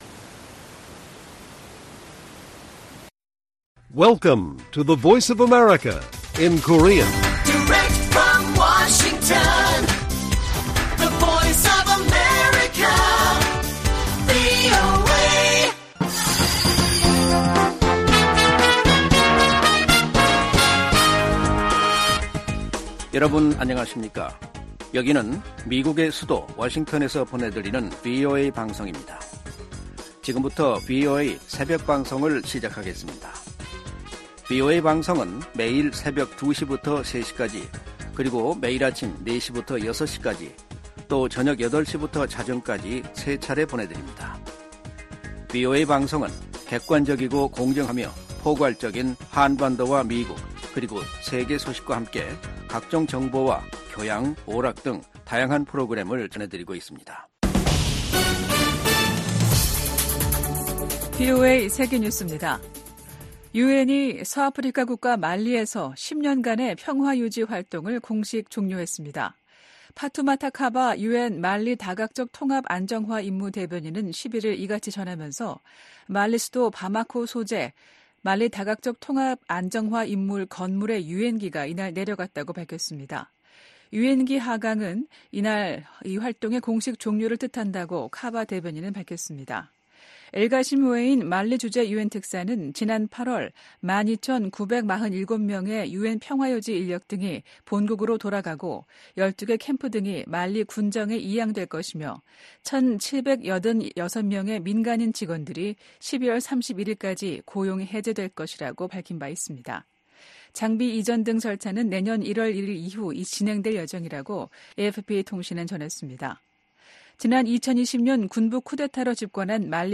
VOA 한국어 '출발 뉴스 쇼', 2023년 12월 12일 방송입니다. 미국과 한국, 일본이 새 대북 이니셔티브를 출범하면서 북한 정권의 핵과 미사일 기술 고도화의 자금줄 차단 등 공조를 한층 강화하겠다고 밝혔습니다. 미 상하원의원들이 중국 시진핑 정부의 탈북민 강제 북송을 비판하며, 중국의 인권이사국 자격 정지 등 유엔이 강력한 대응을 촉구했습니다. 영국 의회가 북한의 불법 무기 개발과 인권 문제 등을 다룰 예정입니다.